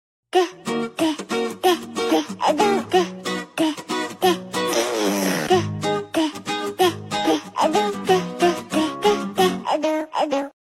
Morning Meow sical 🎵🌞 This kitten sound effects free download
Morning Meow-sical 🎵🌞 This kitten sings every morning to start the day.